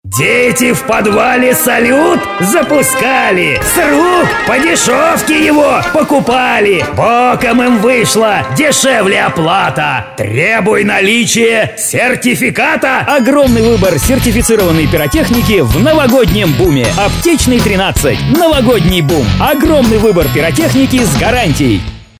Серия аудиороликов на открытие магазина "Новогодний Бум". Ролик в духе детских рифмованных страшилок.